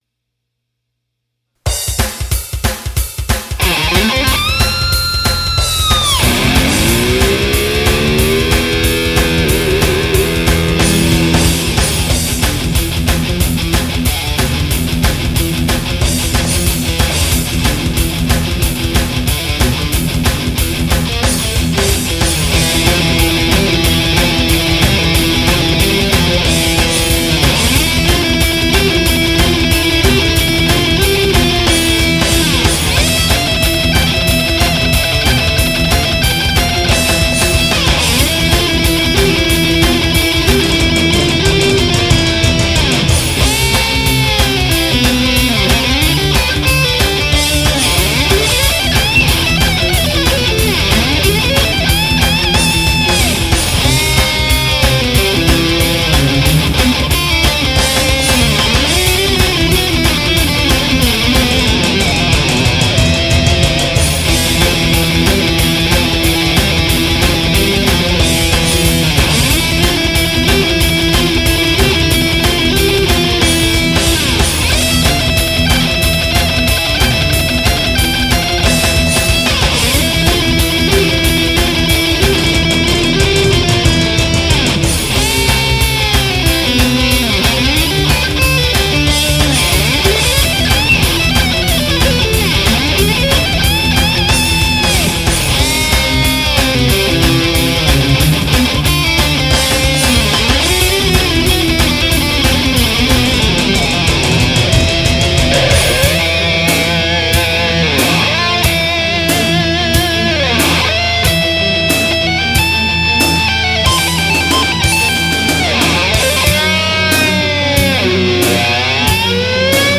Drums: Dr. Rhythm Drum Machine